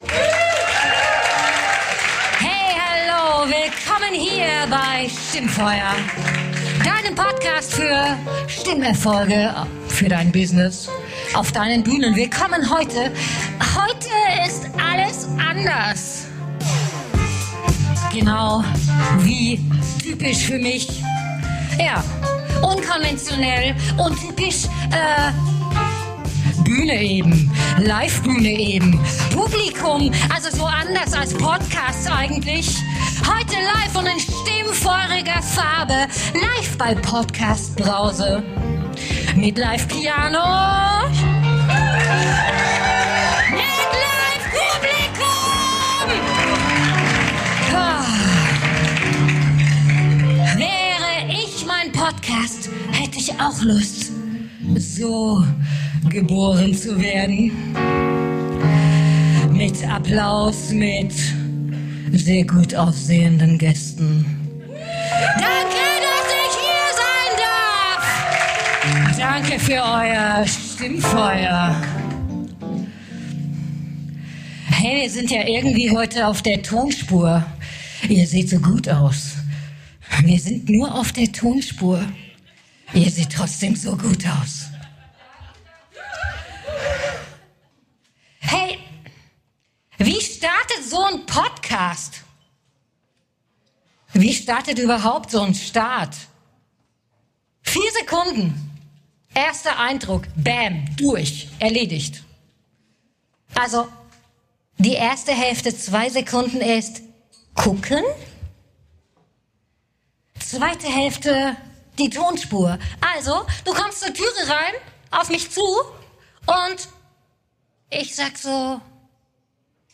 In dieser Episode von STIMMFEUER habe ich mit Live-Publikum getestet, was wir von Pop-Ikonen für deine Business-Stimme, deine Bühnenpräsenz und dein Vocal Branding lernen können. Von „Pokerface“ bis „The show must go on“ – Stimmfeuer frei für diese XXL-LIVE-Episode, die du nicht verpassen solltest!